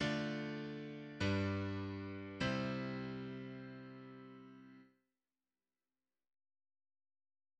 Два последних аккорда представляют собой аутентическую (или совершенную) каденцию с корнями в басу и тоникой в верхнем голосе последнего аккорда: три аккорда образуют прогрессию II–V–I в До мажоре в четырёхголосной гармонии[1].